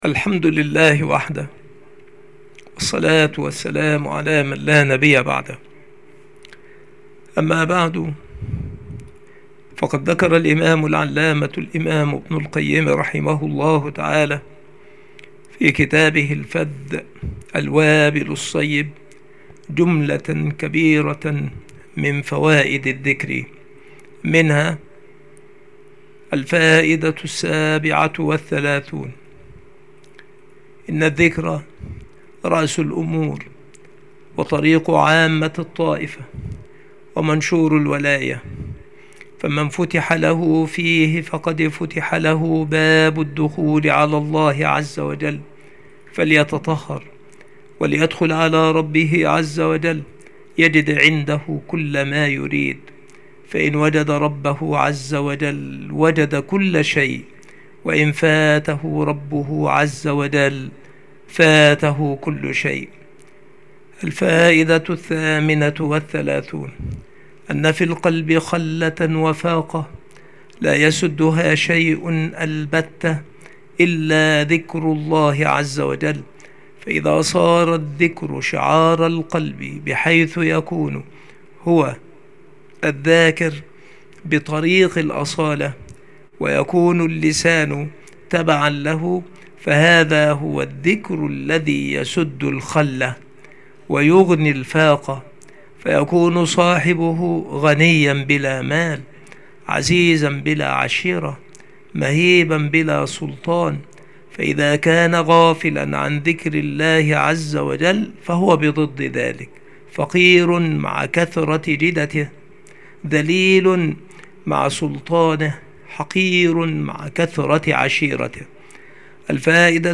المحاضرة
مكان إلقاء هذه المحاضرة المكتبة - سبك الأحد - أشمون - محافظة المنوفية - مصر